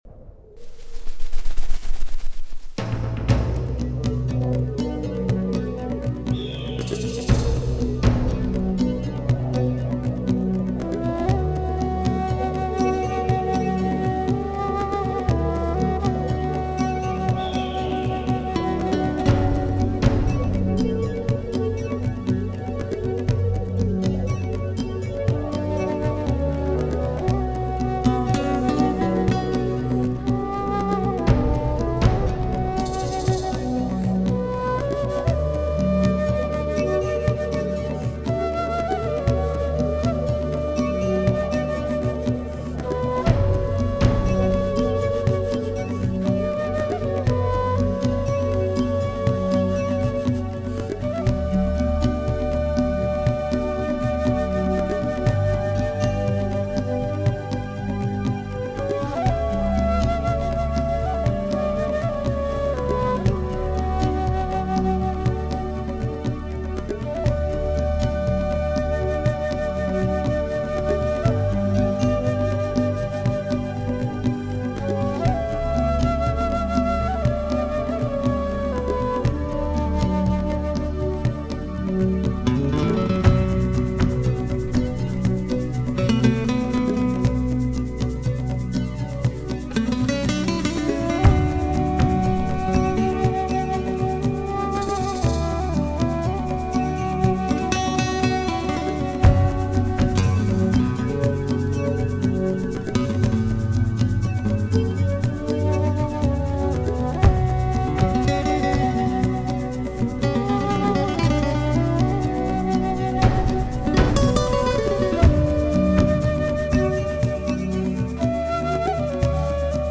相对于其他专辑来说更富有独特的笛声和节奏